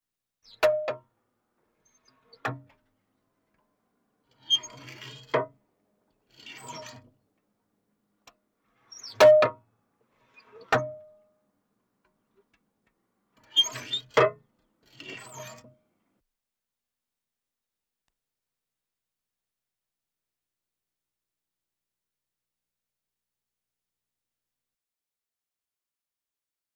transport
Tank M1 Commander Hatch Open Close